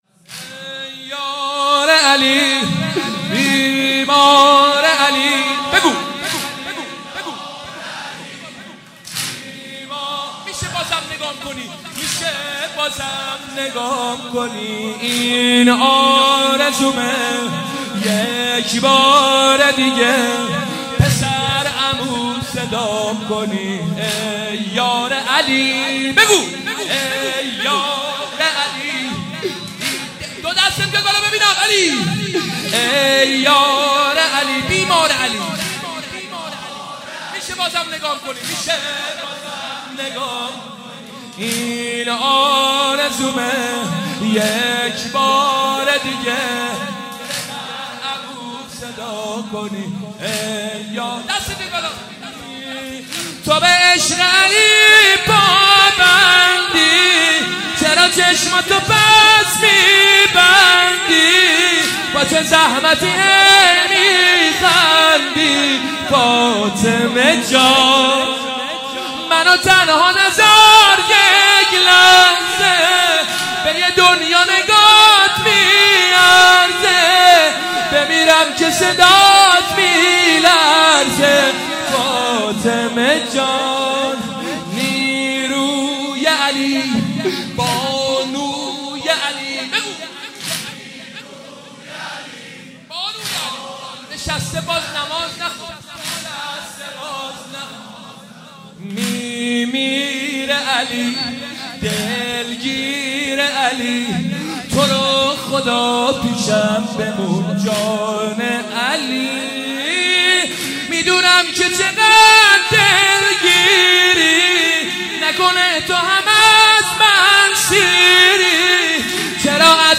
مداحی و نوحه
مداحی فاطمیه ۱۳۹۶
به مناسبت شهادت حضرت زهرا (سلام الله علیها)